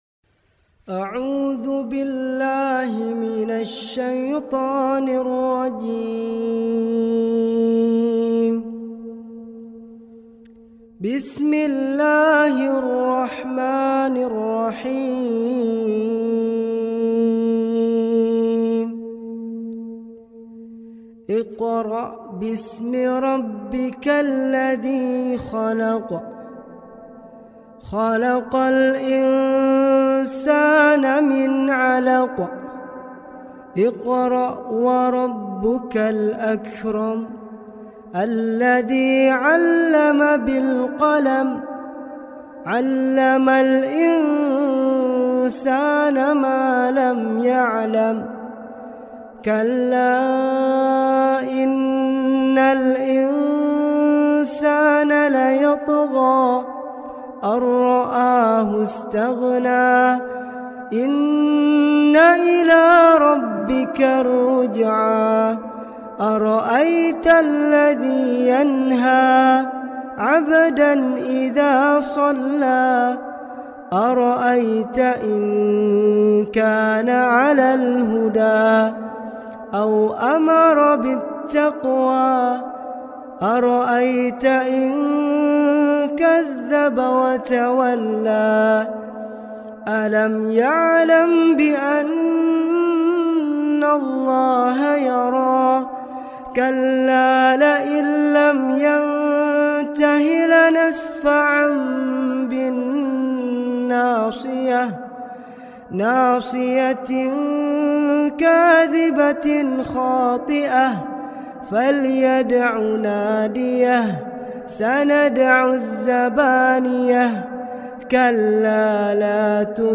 surah Al alaq complete recitation with heart touching voice slowed and reverb